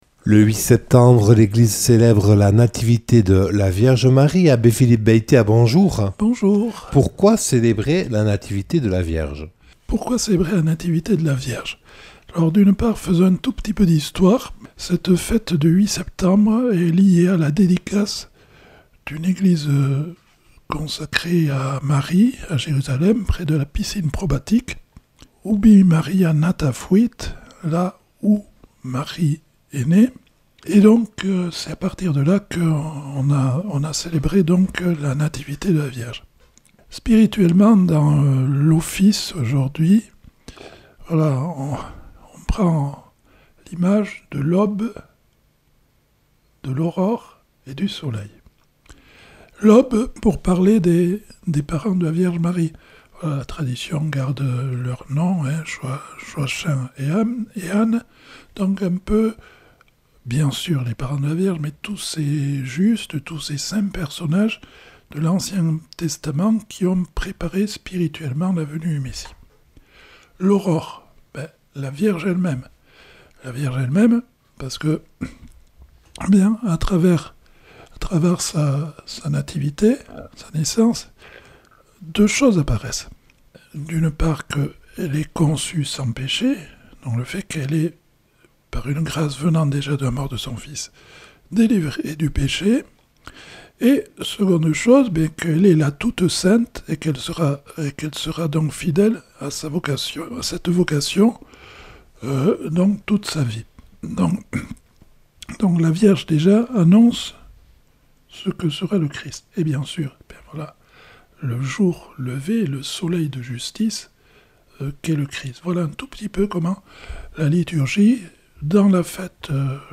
Une émission présentée par